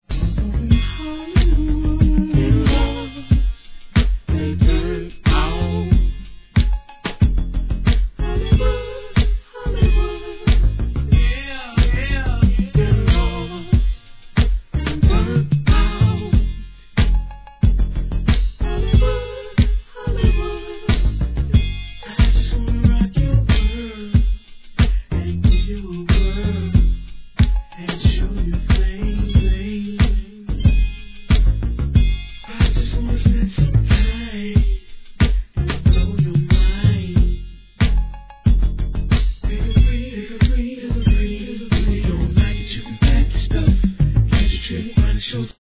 HIP HOP/R&B
上音が印象的で何とも中毒性高いトラックにささやくような歌声が癖になりそうです。